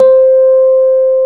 RHODES2S C5.wav